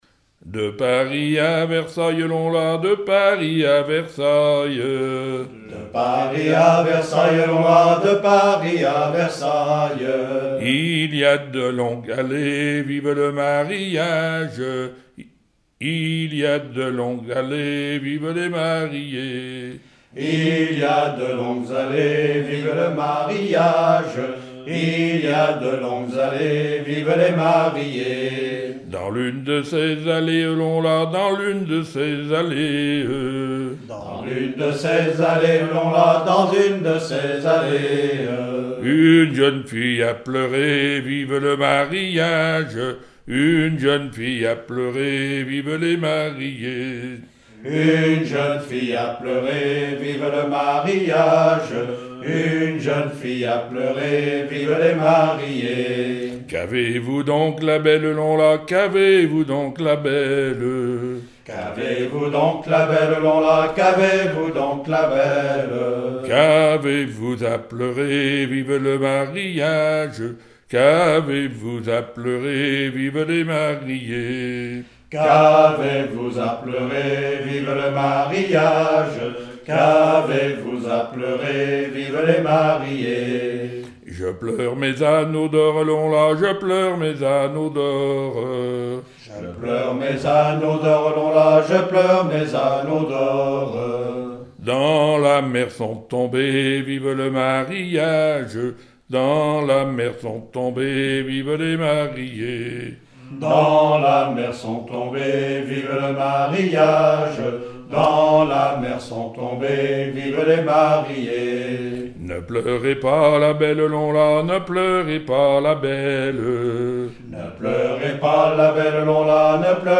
Genre laisse
Collectif cantonal pour le recueil de chansons
Pièce musicale inédite